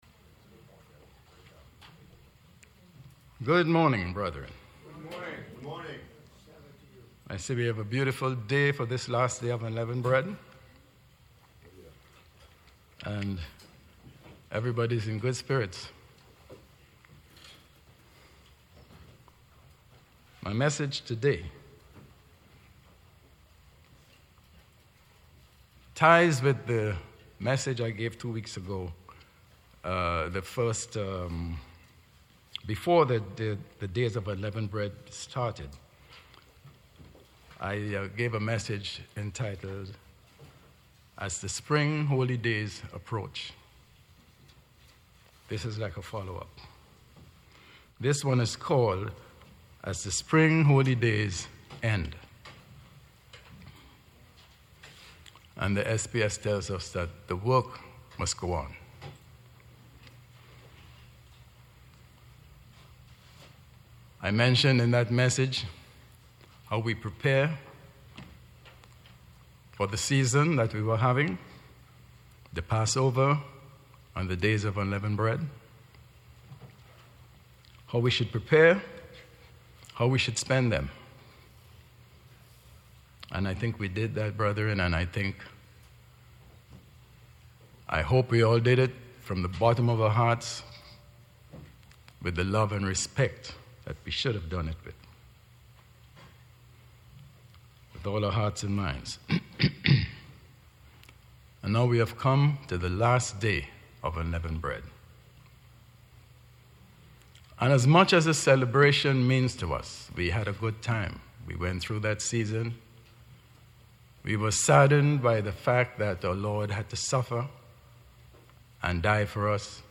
Given in New Jersey - North New York City, NY